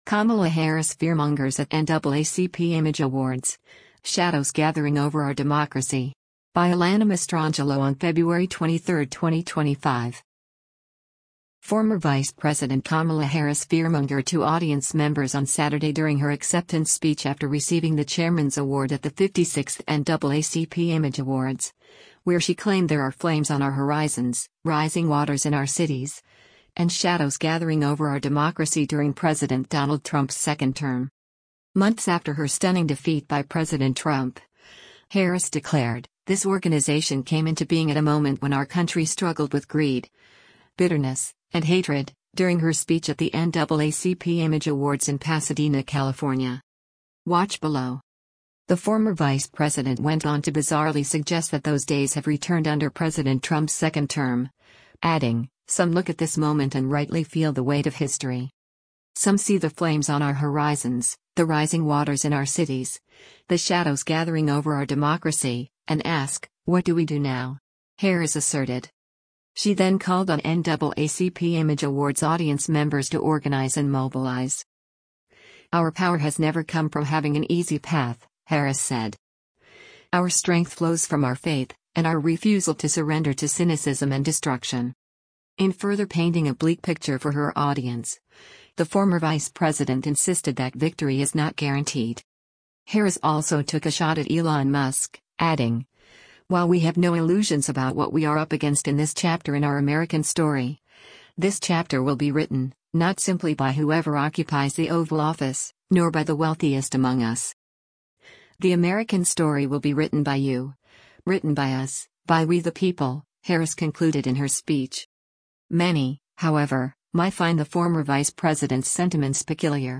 Kamala Harris at the 56th NAACP Image Awards held at the Pasadena Civic Auditorium on Febr
Former Vice President Kamala Harris fearmongered to audience members on Saturday during her acceptance speech after receiving the Chairman’s Award at the 56th NAACP Image Awards, where she claimed there are “flames on our horizons, rising waters in our cities,” and “shadows gathering over our democracy” during President Donald Trump’s second term.